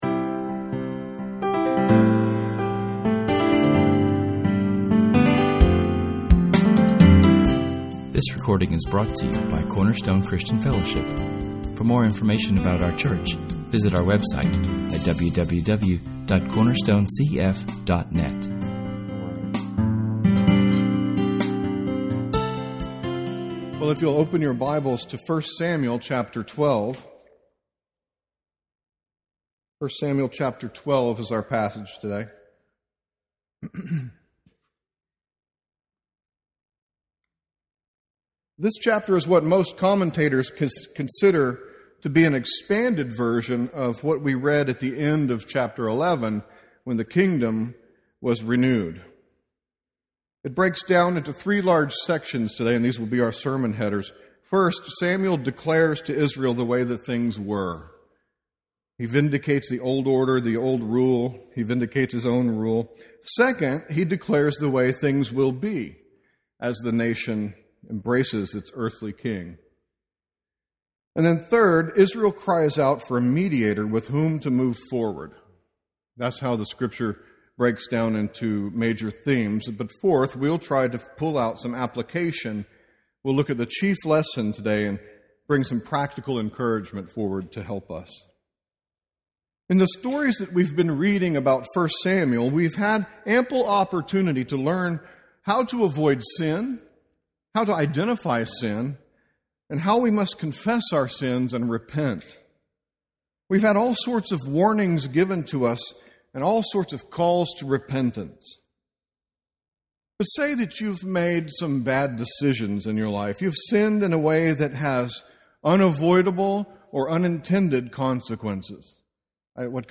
In this sermon we speak much of covenants, the first and second Adam, and how we need to be born of the proper father.